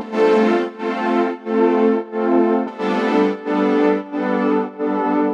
Index of /musicradar/sidechained-samples/90bpm
GnS_Pad-MiscB1:4_90-A.wav